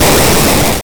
Monster1.wav